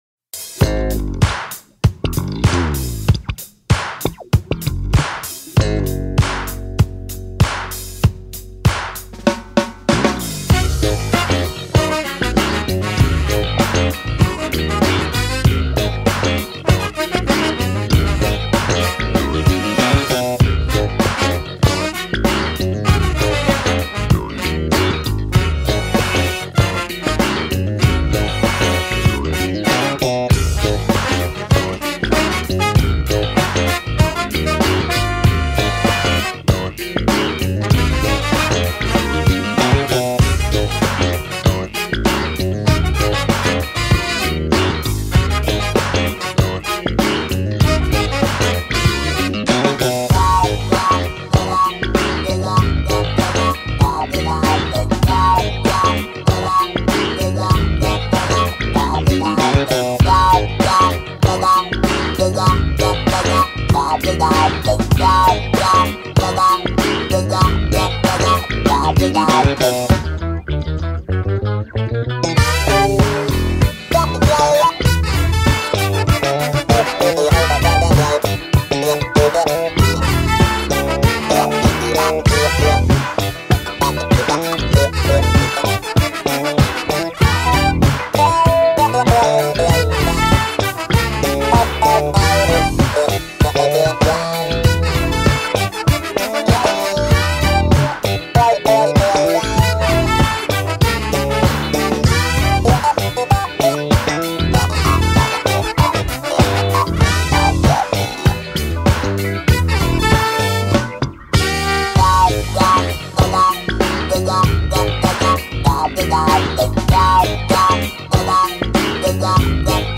the legendary drummer from the iconic funk band